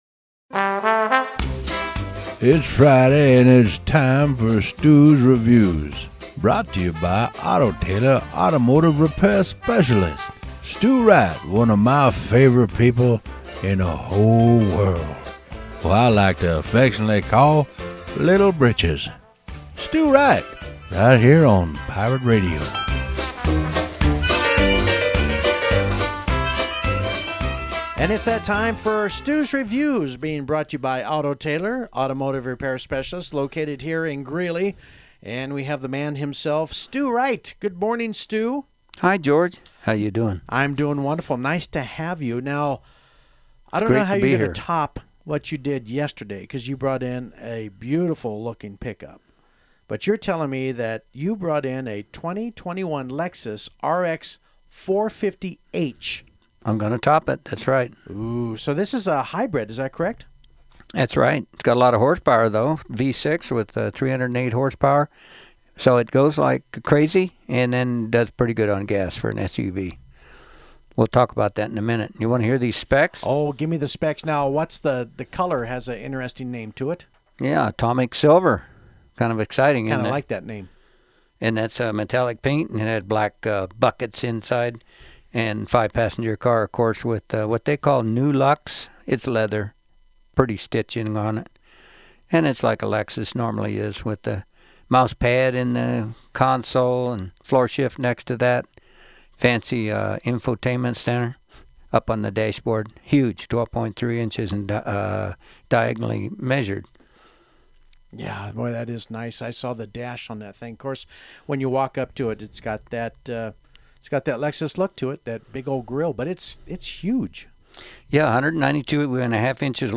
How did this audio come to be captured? Radio review at Pirate Radio studios